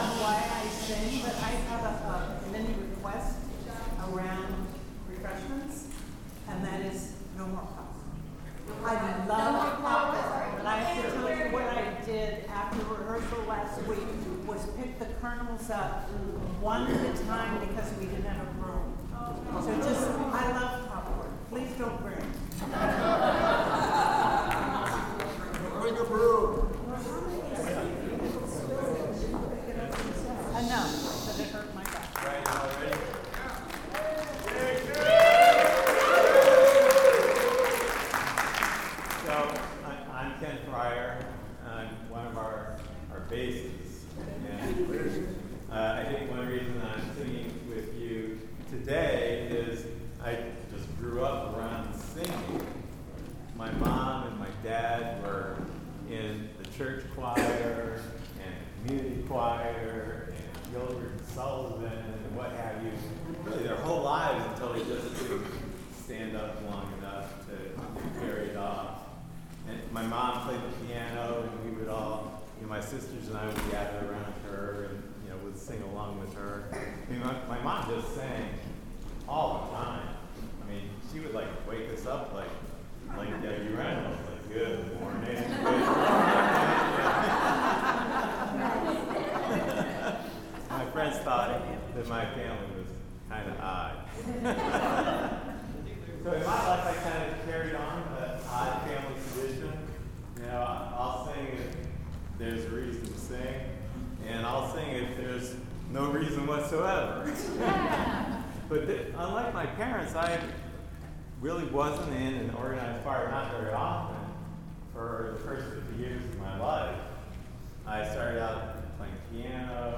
Recording stopped at 11:16, followed by discussion and rehearsing movement 3. 28:25 - Notes for rehearsing movement 4 next week, and rehearsing Cry.